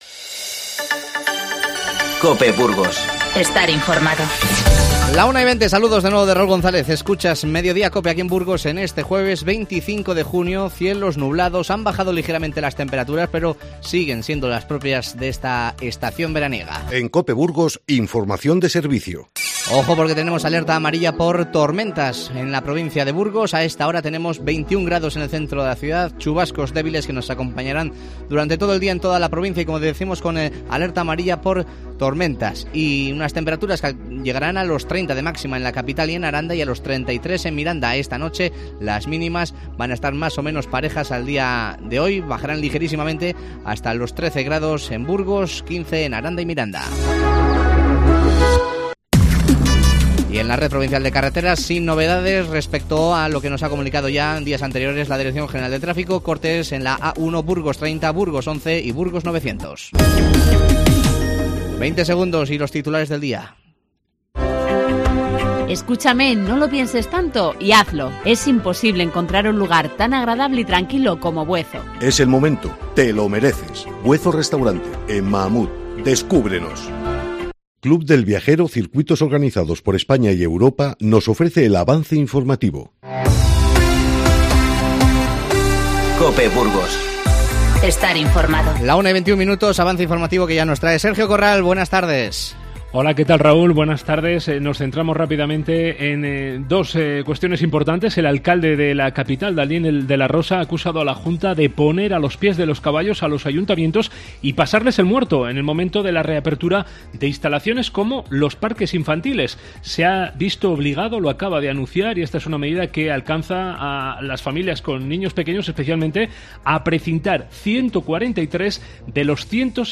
Te avanzamos las principales noticias del día y escuchamos el tercer reportaje sobre las fiestas de Burgos, centrado en esta ocasión en el espectáculo de los títeres.